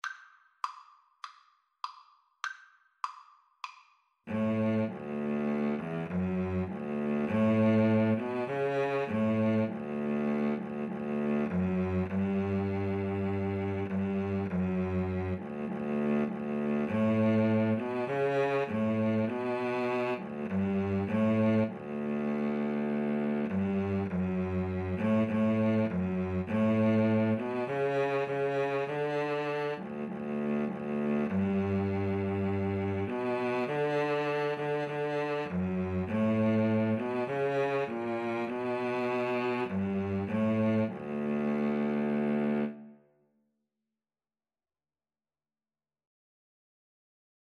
Free Sheet music for Cello Duet
D major (Sounding Pitch) (View more D major Music for Cello Duet )
4/4 (View more 4/4 Music)